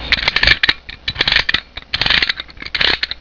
Meccano_Clockwork_Motor_Winding_3sec.wav